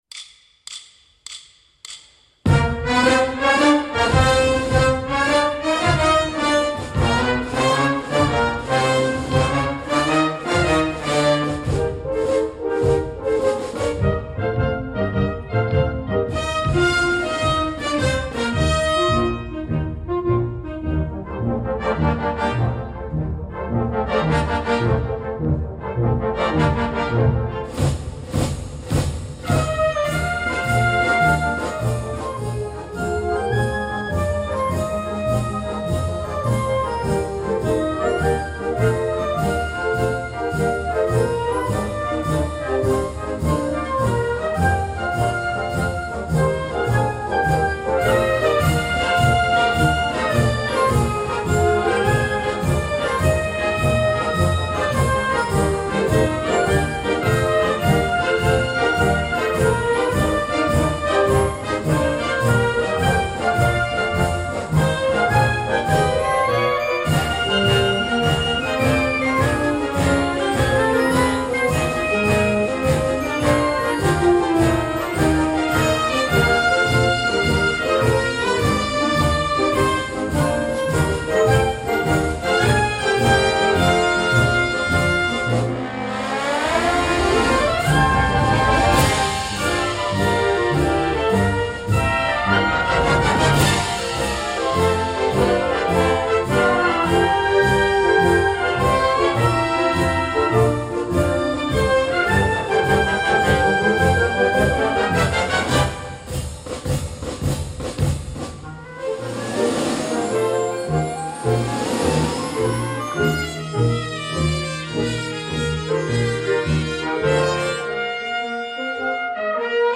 Oggi “Incontri d’estate” ospita Franco Piersanti , compositore di fama internazionale, autore di colonne sonore iconiche per il cinema e la televisione italiana. Durante la puntata, Piersanti ci parlerà del suo percorso musicale, della collaborazione con registi di fama internazionale e dell’incontro tra suono e immagine.